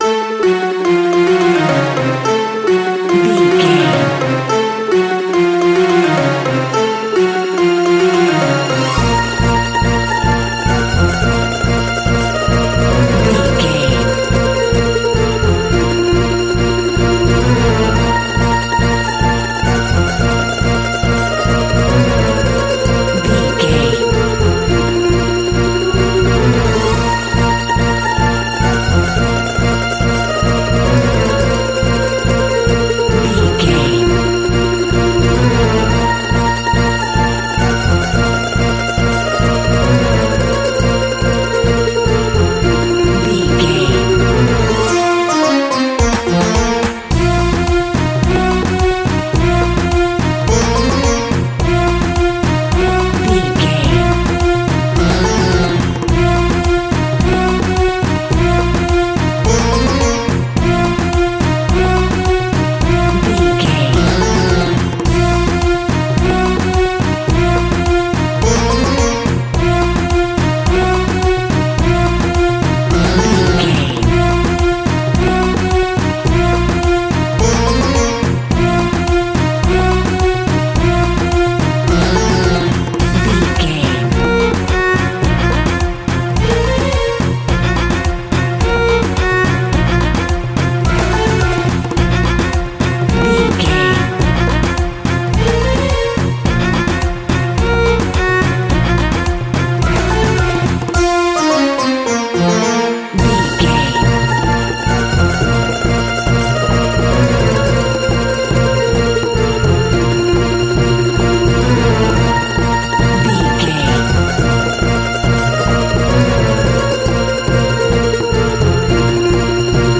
Aeolian/Minor
B♭
World Music
percussion